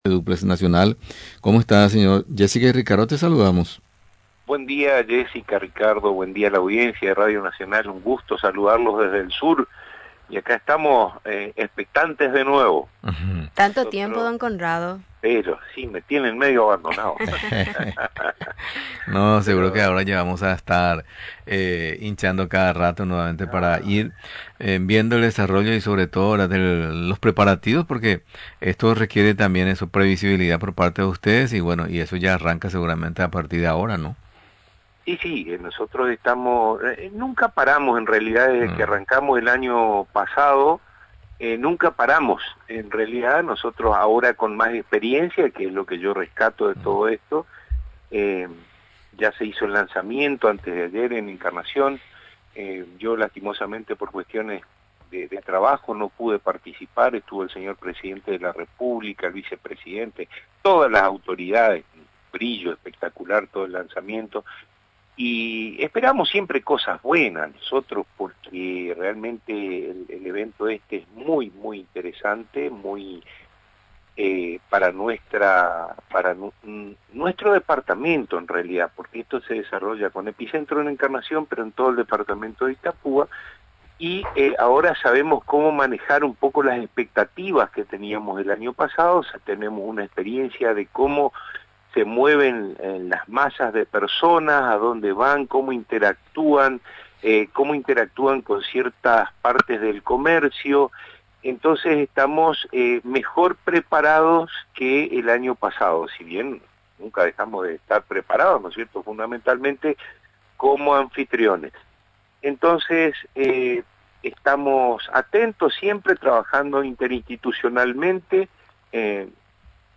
En una entrevista con Radio Nacional